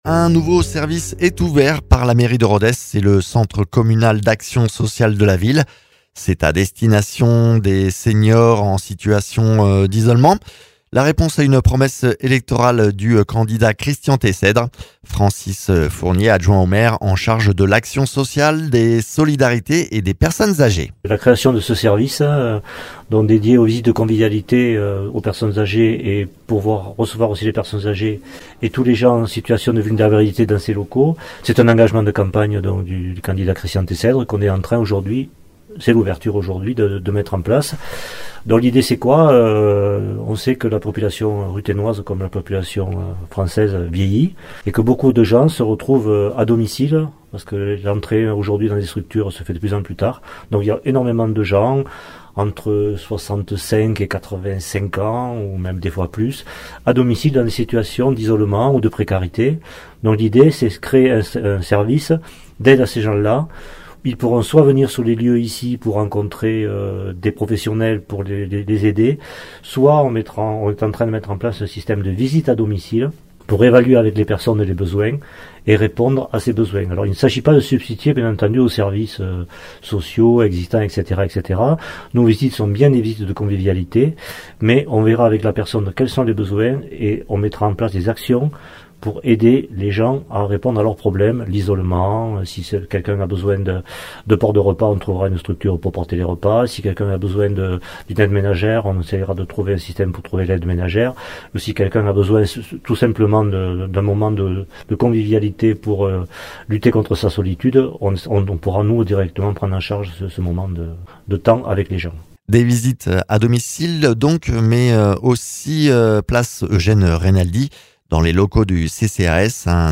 Interviews
Invité(s) : Francis Fournié, adjoint au maire de Rodez en charge de l’action sociale, des solidarités et des personnes agées